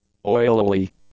oilily pronunciation help?